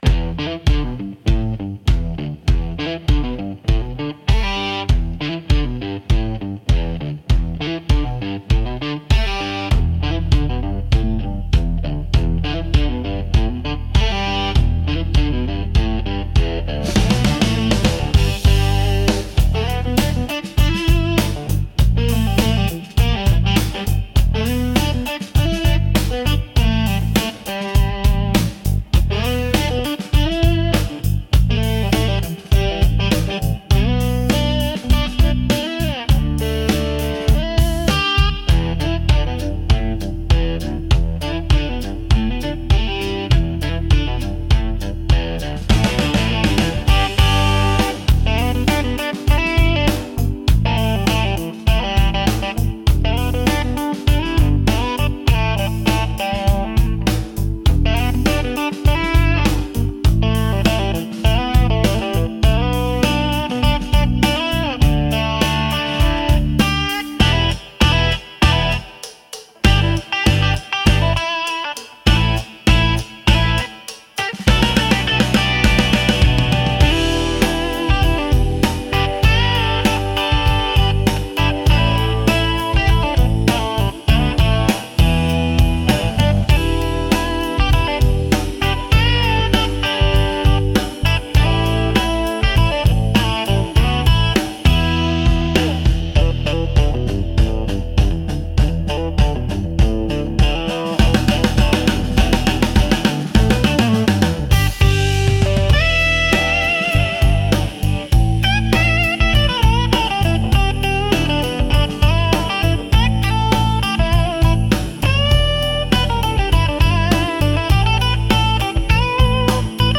Instrumental - Real Liberty Media DOT com - 3.08